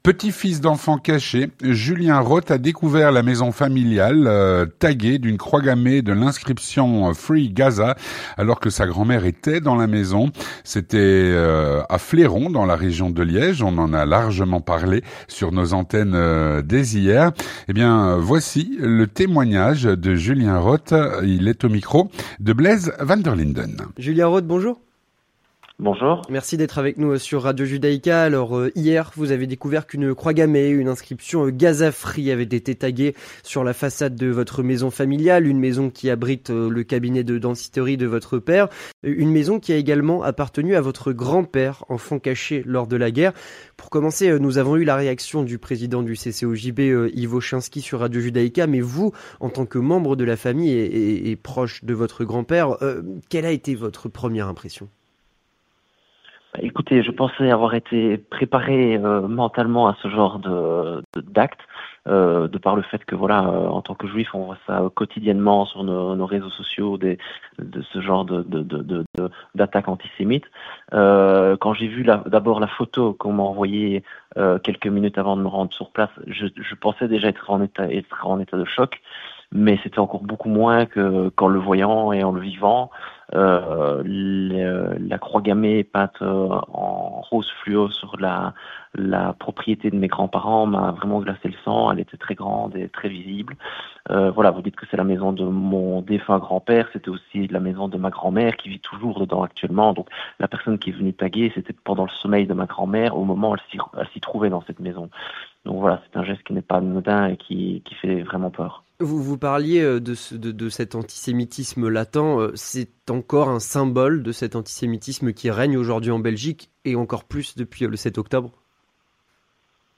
L'entretien du 18H - Tag antisémite sur une maison juive à Fléron, près de Liège.